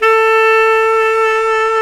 SAX TENORB0X.wav